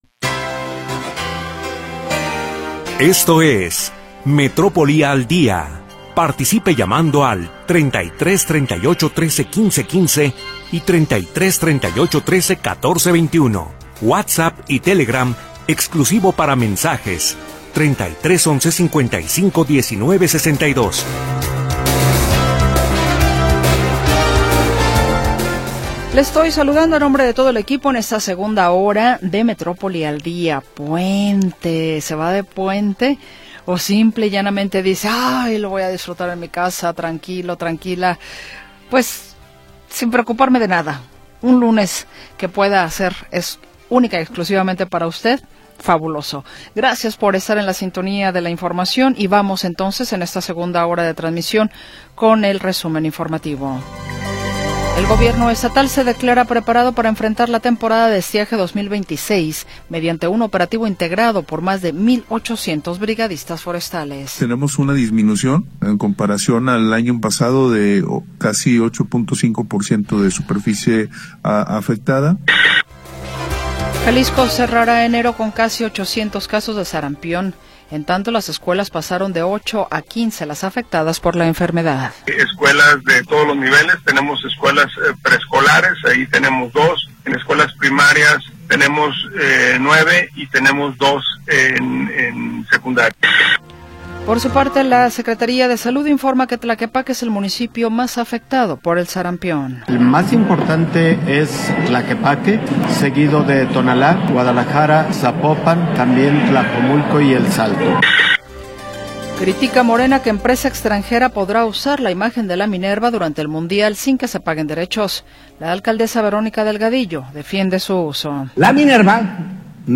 La historia de las últimas horas y la información del momento. Análisis, comentarios y entrevistas